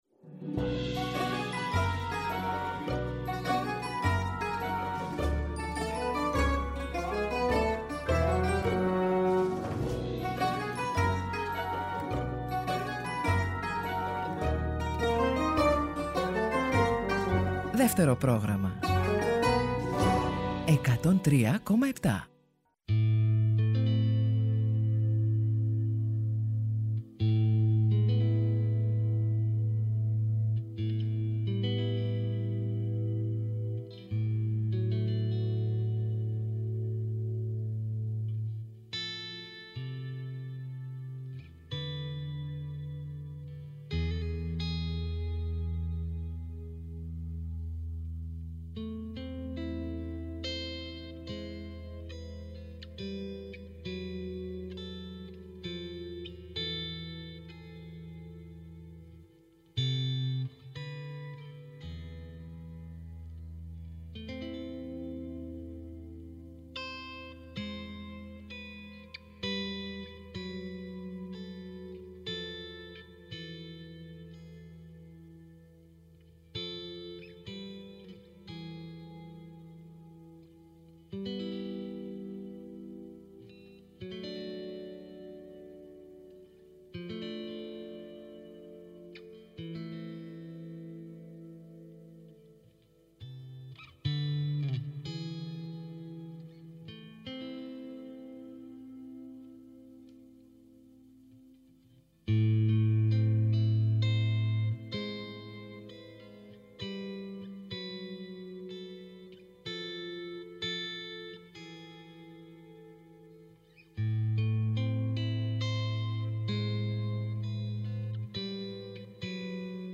Μέσω τηλεφωνικής σύνδεσης ακούστηκαν οι: